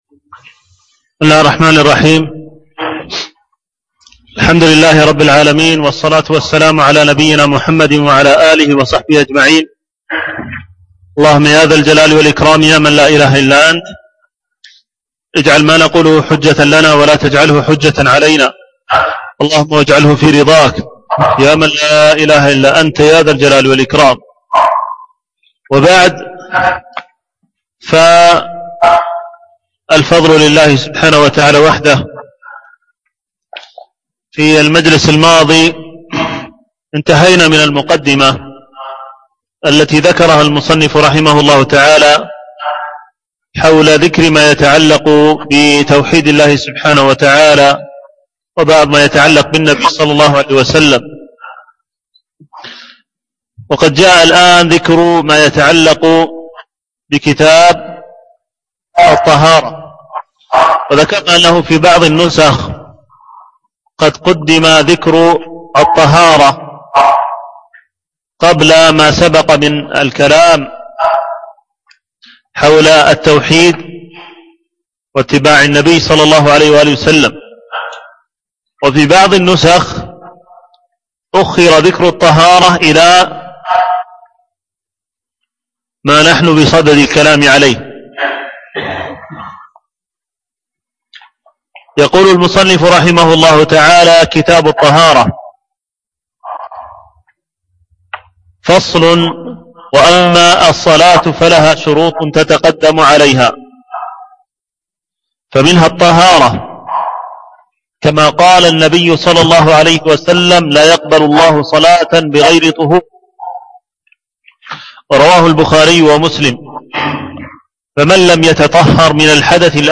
شرح منهج السالكين وتوضيح الفقه في الدين - الدرس الثالث